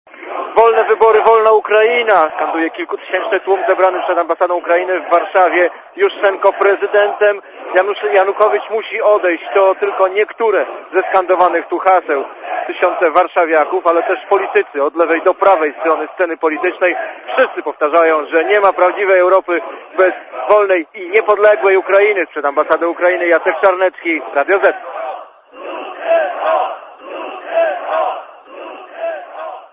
Relacja reportera Radia ZET
maifestacjaambasada.mp3